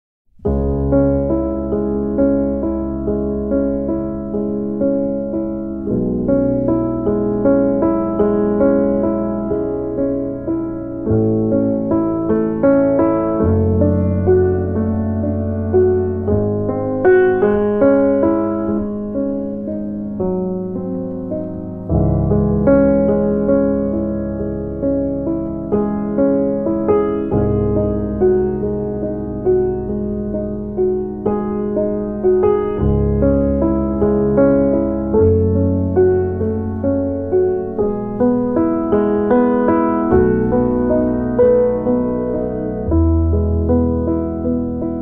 piano
bass
drums
美しきクラシックのスタンダード・チューンをス インギンなジャズのフィーリングで力強く、そして優雅にプレイ！